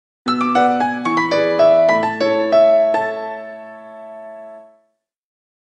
Kategori Telefon